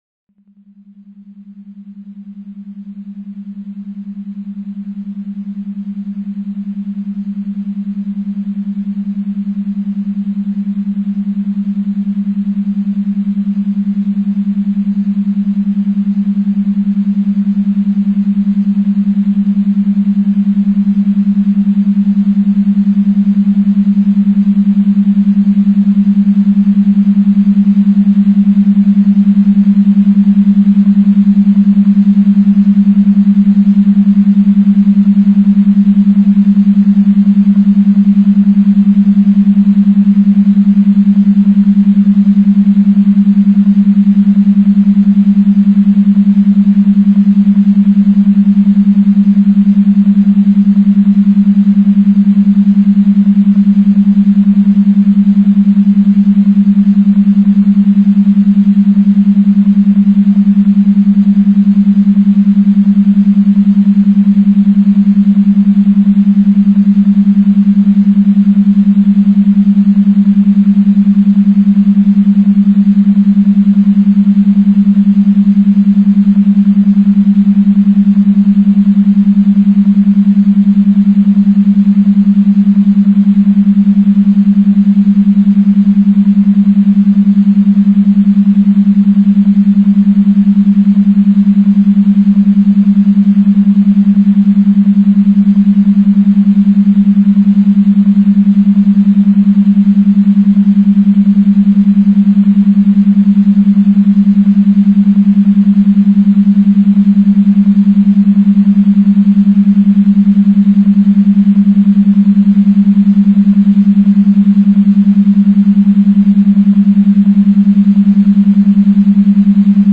Цифровой наркотик (аудио наркотик) Кокаин